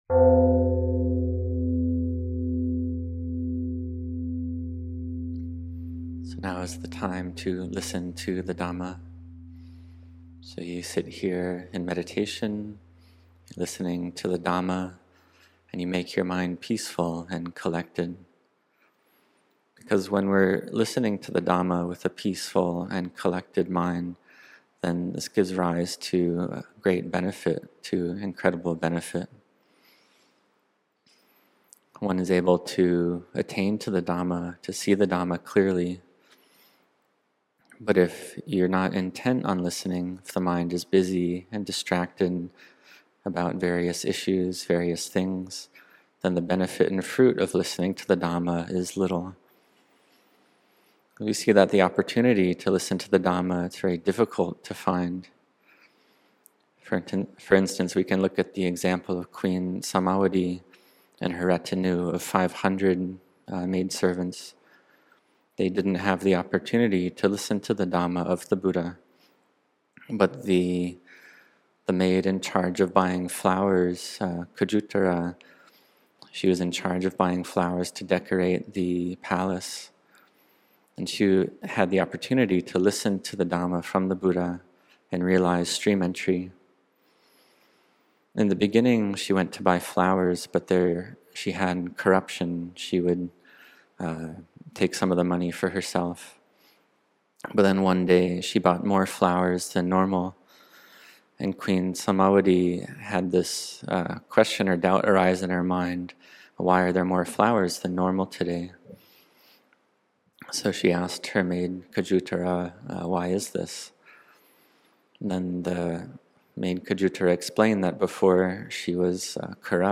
Listening to Dhamma, Understanding Dhamma | Retreat Day 2 Morning | 27 July 2025